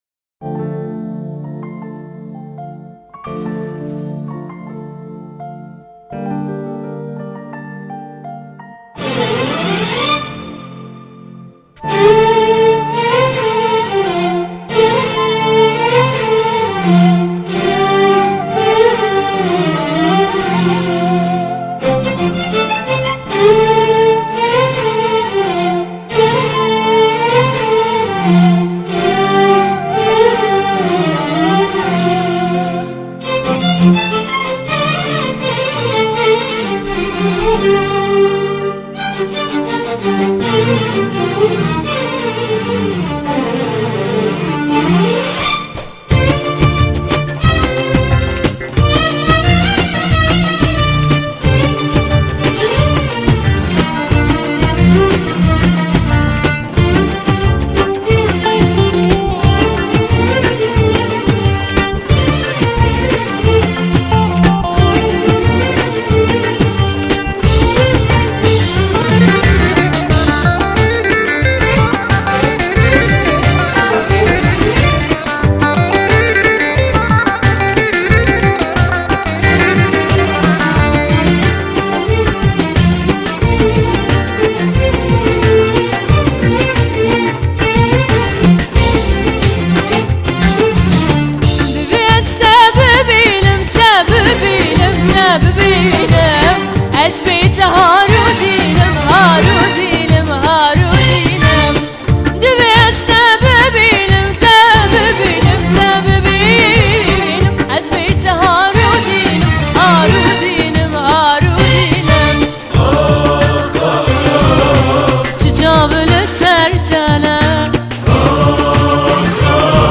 آهنگ کردی پاپ